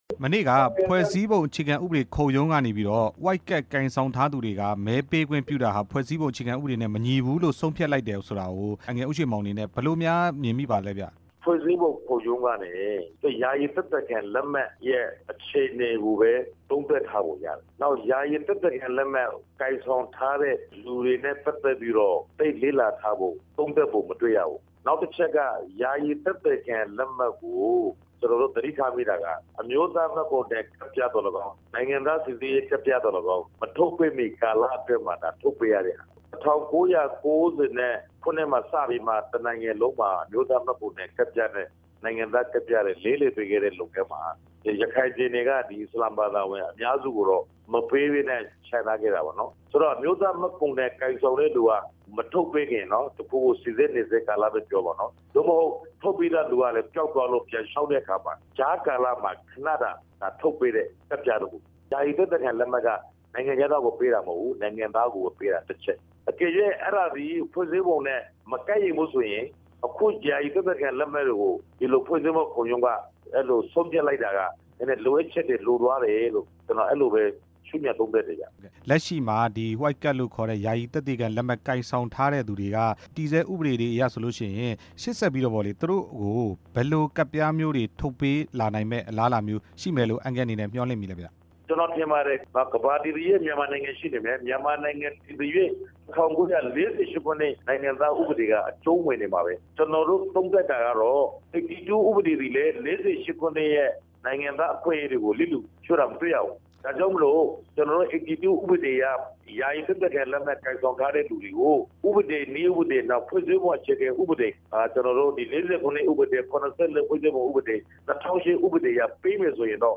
ယာယီသက်သေခံလက်မှတ် (ဝှိုက်ကဒ်) ကိုင်ဆောင်ထားသူတွေကို မဲပေးခွင့်ပြုတယ်ဆိုတာ ဖွဲ့စည်းပုံအခြေခံ ဥပဒေနဲ့ မညီဘူးလို့ ဖွဲ့စည်းပုံ အခြေခံဥပဒေခုံရုံးက မနေ့က ဆုံးဖြတ်လိုက်ပါတယ်။ ရခိုင်ပြည်နယ် ဘူးသီးတောင်မြို့နယ် ပြည်သူ့လွှတ်တော်ကိုယ်စားလှယ် ဦးရွှေမောင်က ယာယီသက်သေခံ လက်မှတ်ကိုင်ဆောင်ထားသူတွေကို နိုင်ငံသားစိစစ်ရေးကတ် ထုတ်ပေးသင့်ကြောင်း ပြောပါတယ်။